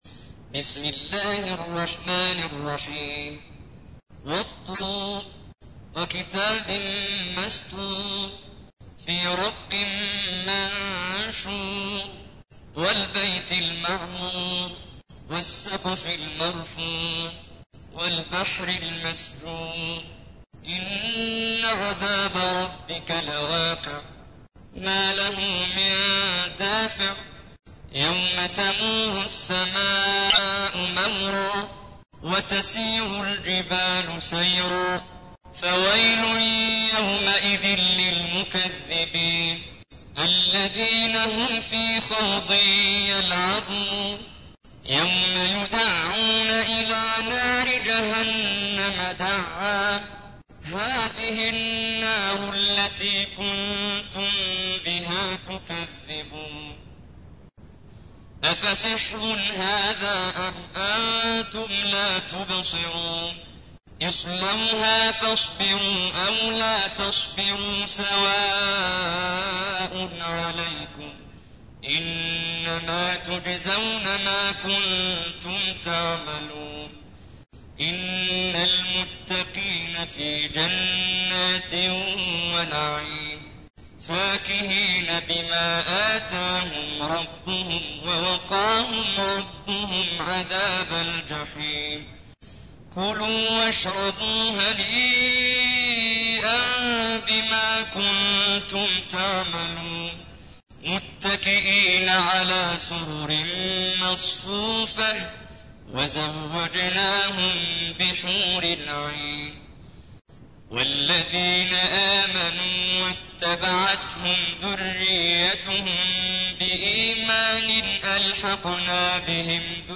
تحميل : 52. سورة الطور / القارئ محمد جبريل / القرآن الكريم / موقع يا حسين